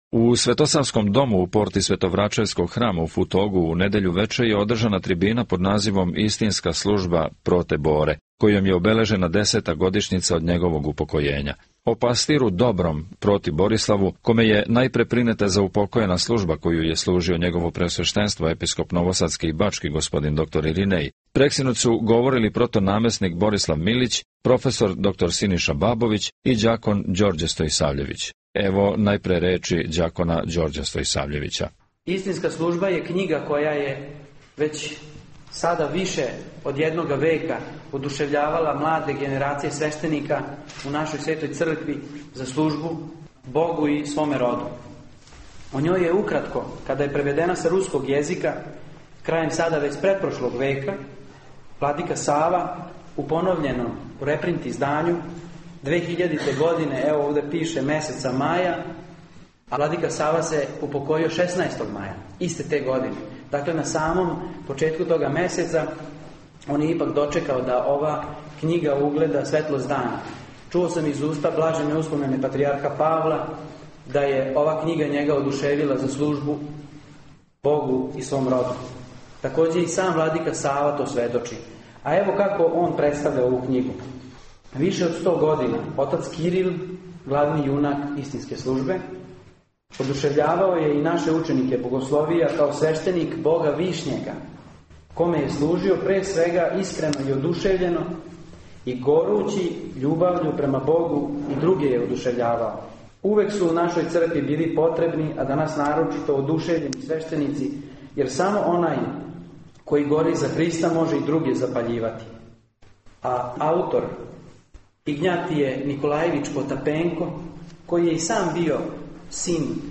Футог